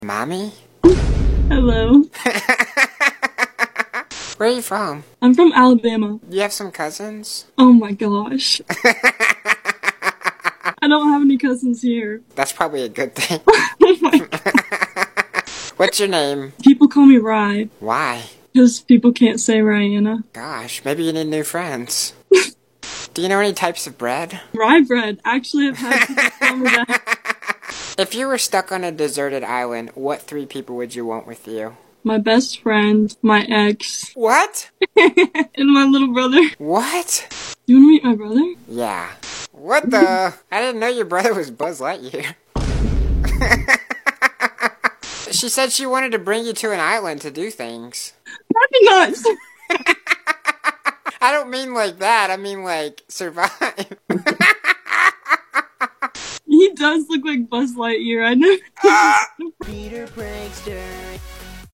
Funny conversation with a puppet